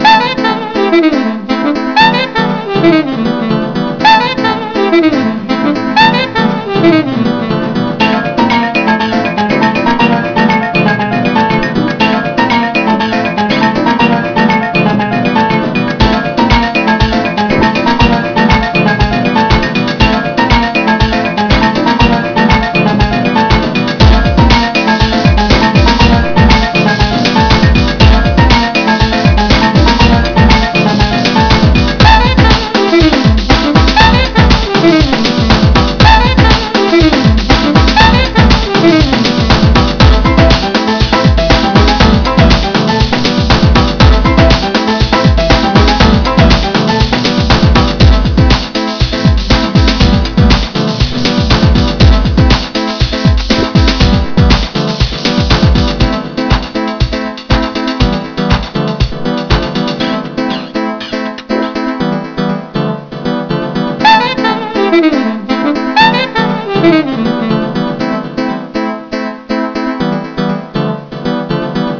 Jazzy.wav